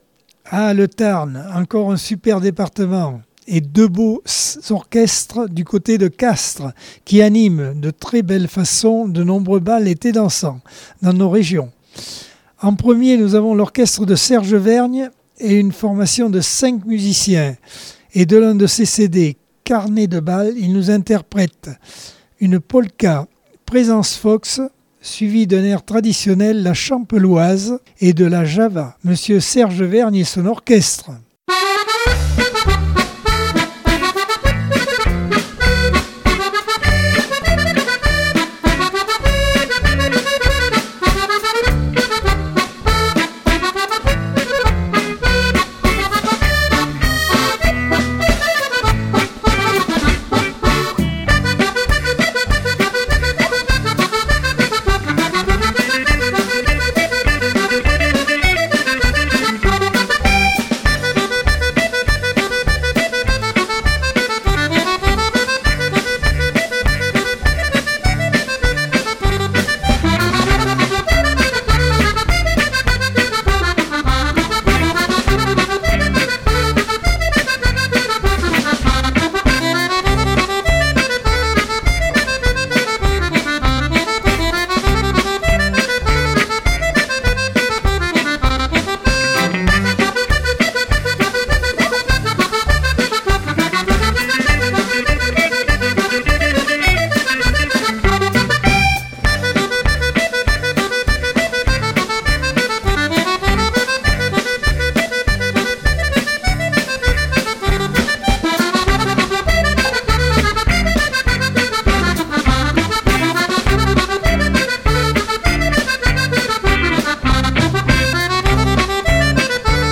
Accordeon 2022 sem 51 bloc 4 - ACX Vallée de la Dordogne